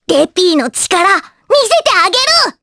voices / heroes / jp
Rephy-Vox_Skill4_jp.wav